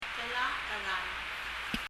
カナ表記、ムリヤリすれば、 「・・・テラ、 ア（エ）ラル」 アラルともエラルとも、曰く言いがたい音（ 発音記号でいうと、 æ なんでしょうかね・・・）